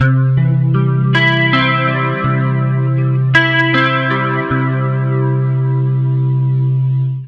80MAJARP C-L.wav